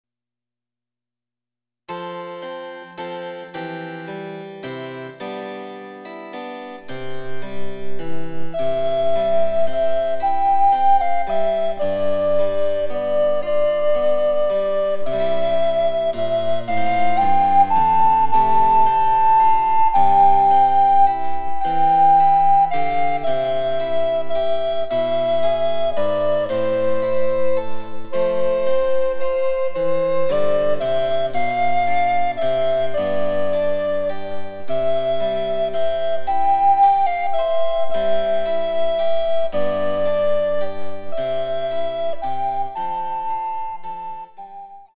チェンバロ伴奏で楽しむ日本のオールディーズ、第４弾！
※伴奏はモダンピッチのみ。